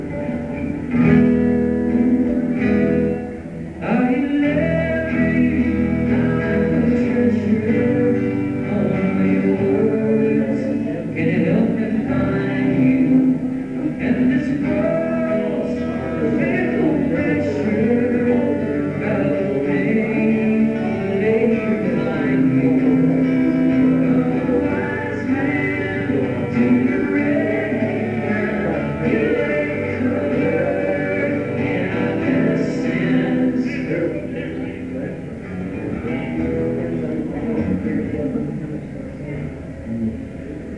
soundcheck